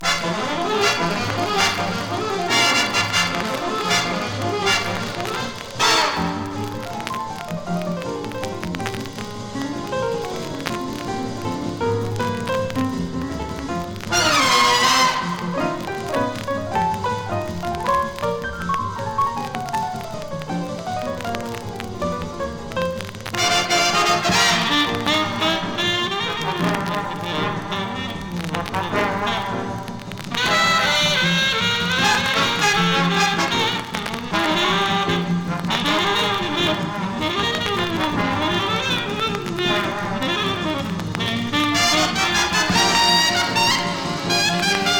Jazz　USA　12inchレコード　33rpm　Mono
ジャケ擦れ汚れ背ダメージ　盤キズ多チリノイズ多